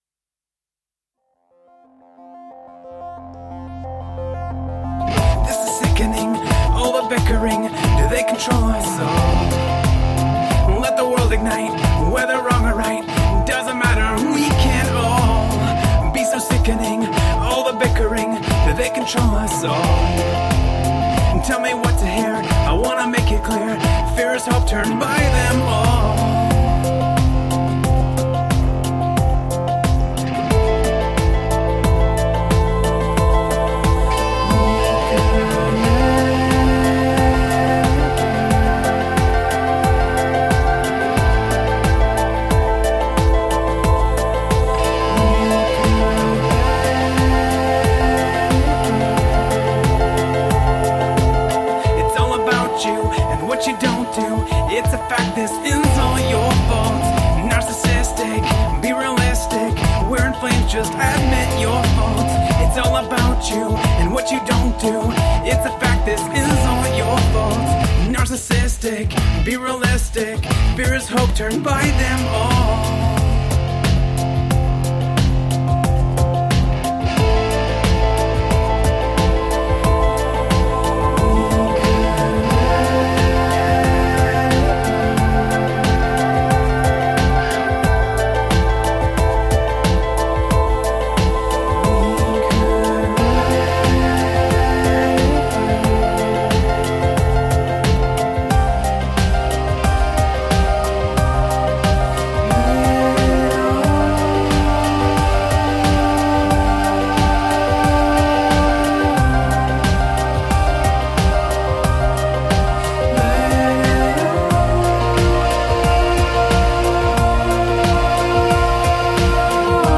Tagged as: Electro Rock, Alt Rock, Prog Rock